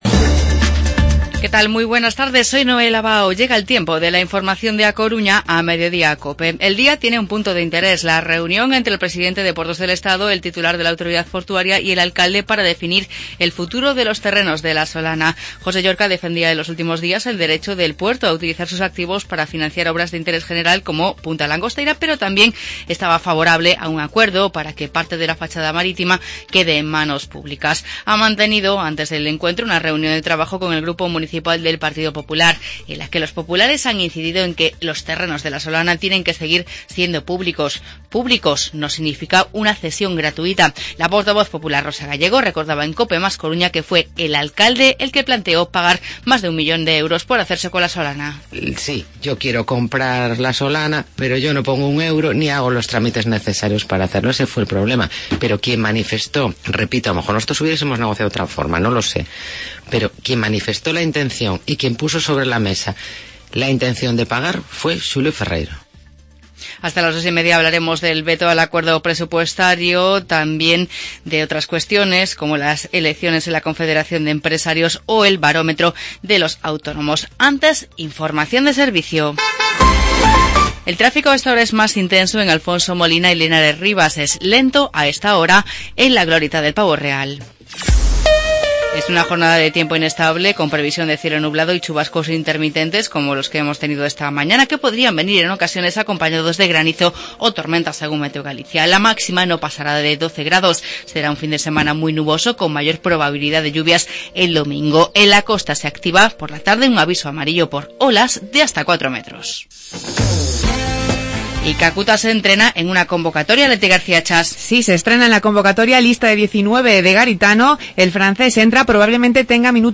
Informativo Mediodía COPE Coruña viernes, 27 de enero de 2017